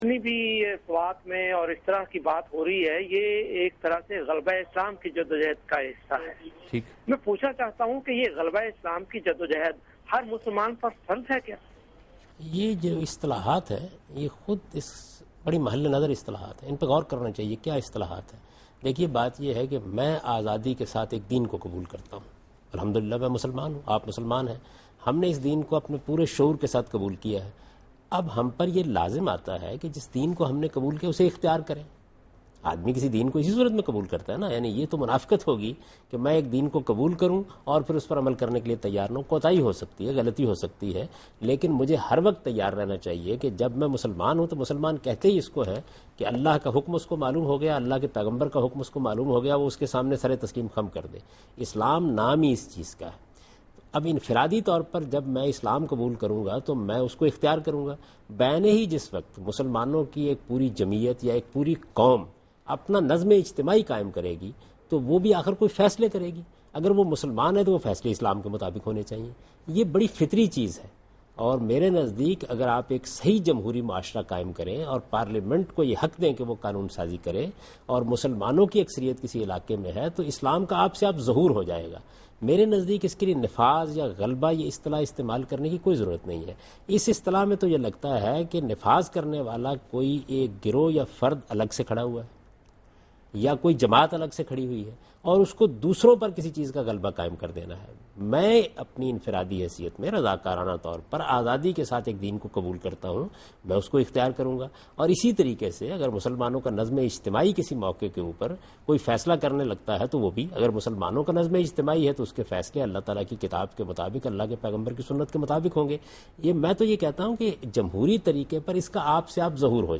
Category: TV Programs / Dunya News / Deen-o-Daanish / Questions_Answers /